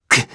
Roman-Vox_Damage_jp_02.wav